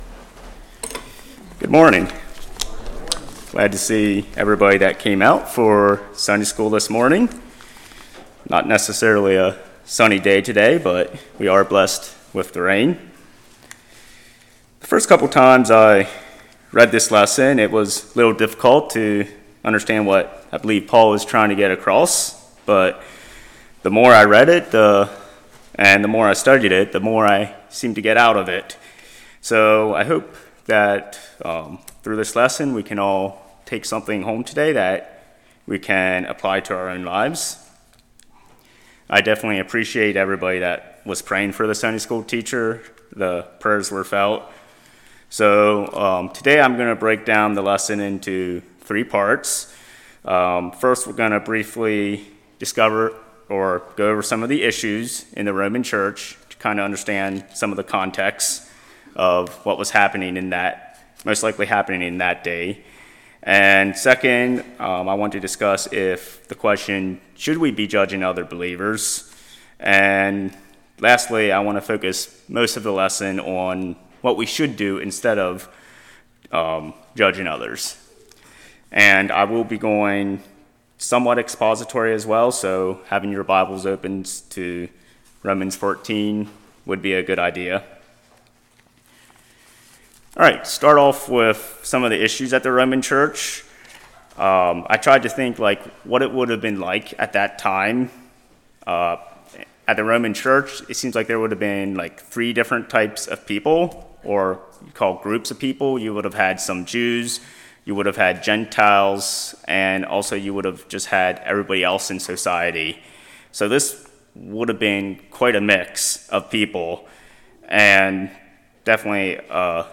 Romans 14:10-23 Service Type: Sunday School Issues in the Roman Church.